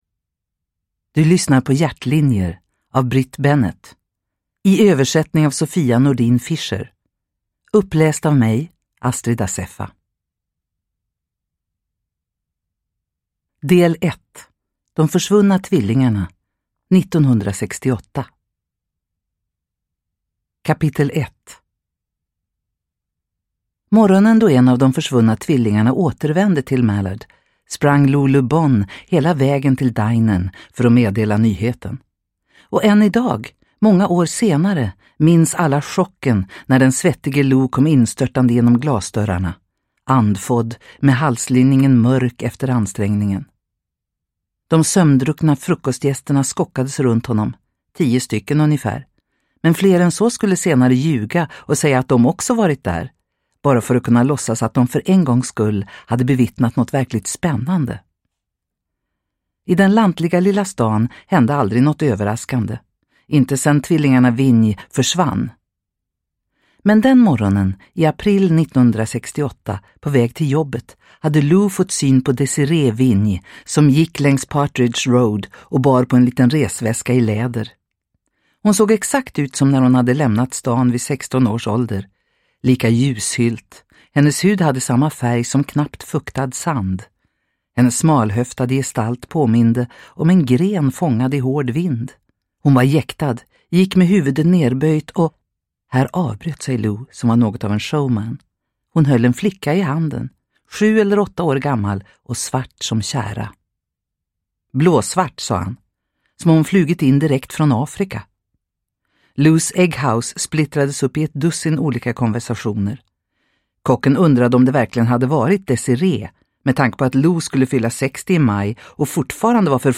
Hjärtlinjer – Ljudbok – Laddas ner